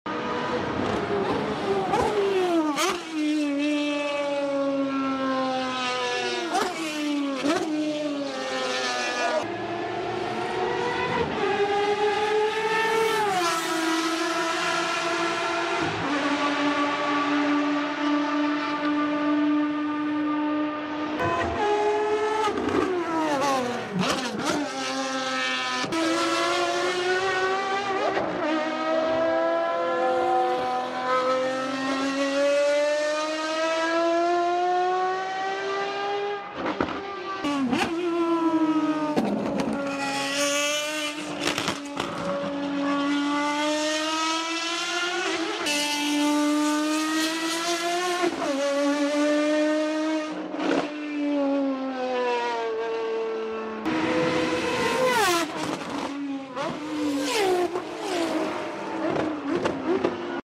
Ferrari FXX Evoluzione glowing brakes and screaming V12.